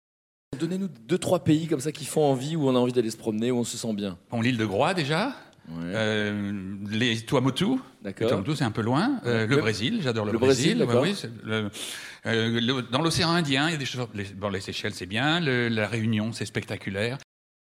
(entendu sur France Inter "on-va-tous-y-passer" le 2/11)